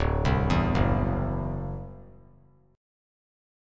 D9 Chord
Listen to D9 strummed